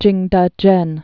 (jĭngdŭjĕn)